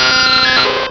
Cri de Nosferapti dans Pokémon Rubis et Saphir.